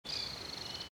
forest_bird7.mp3